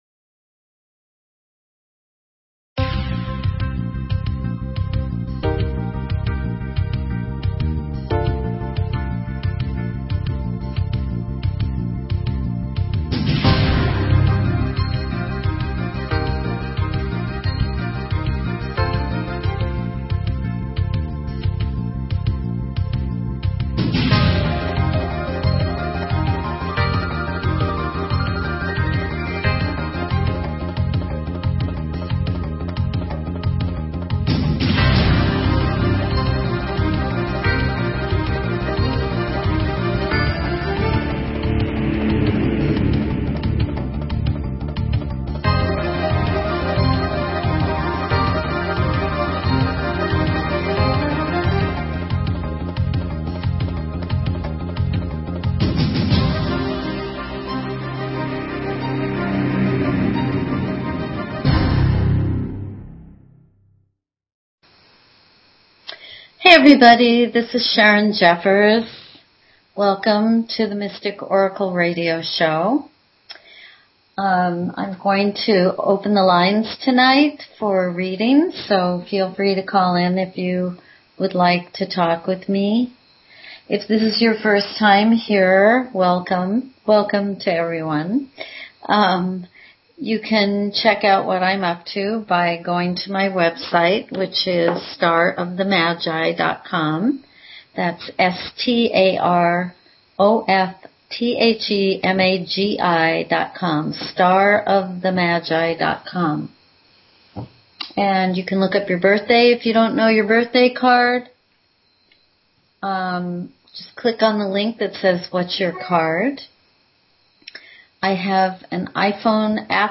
Talk Show Episode, Audio Podcast, The_Mystic_Oracle and Courtesy of BBS Radio on , show guests , about , categorized as
Discover the secrets hidden in your birthday, your relationship connections, and your life path. Open lines for calls.